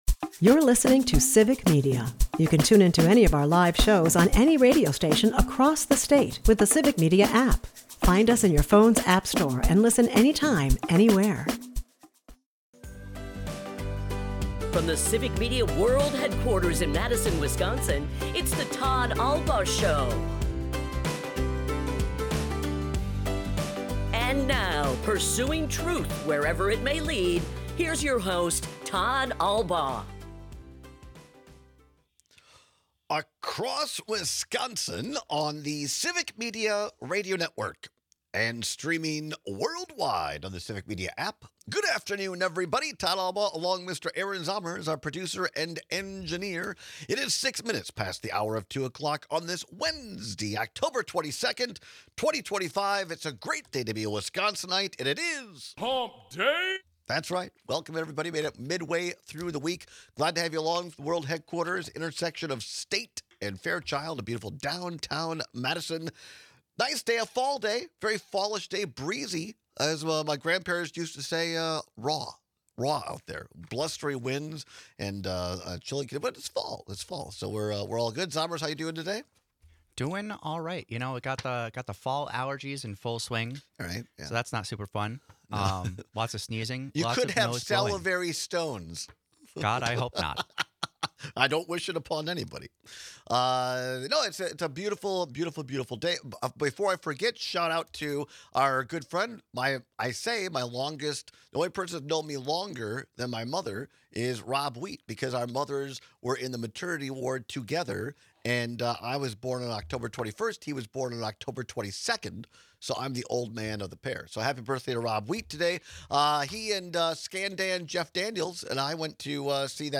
radio network and airs live Monday through Friday from 2-4 pm across Wisconsin